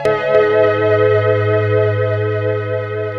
Synth Chord 01.wav